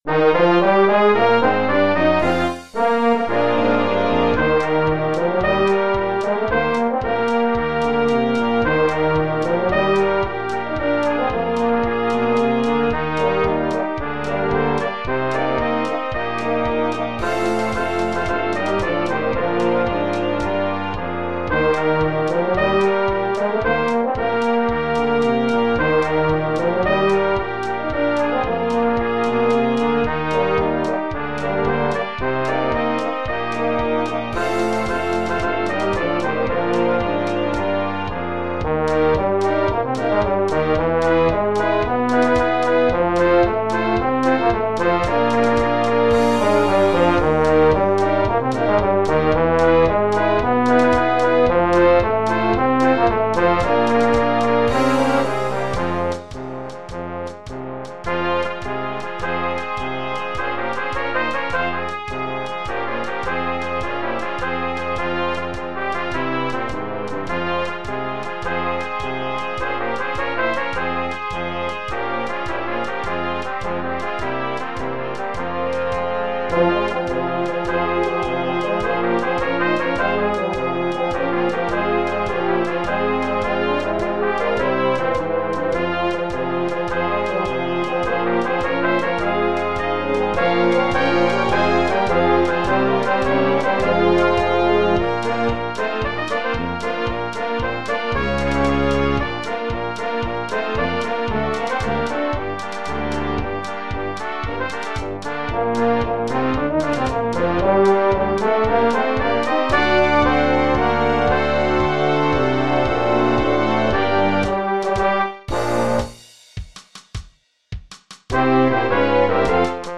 This cheerful, upbeat concert march
for brass ensemble